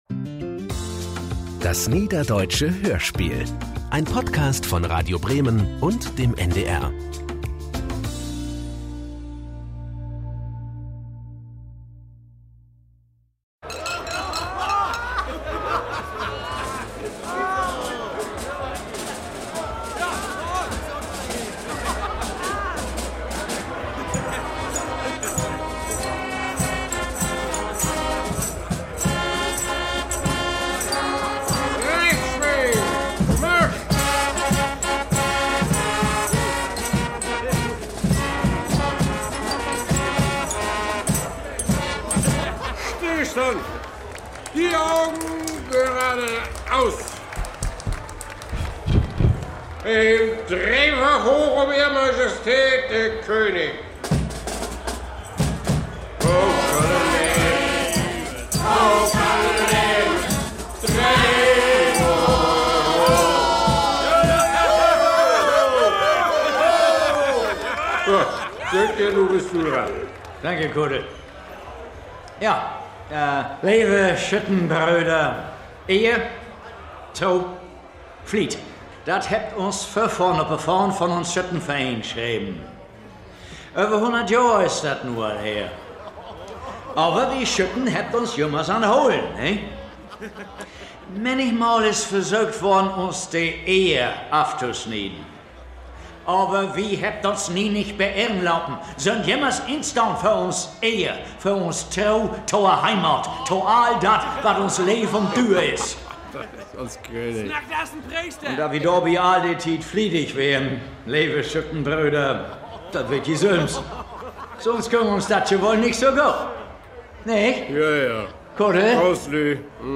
Niederdeutsches Hörspiel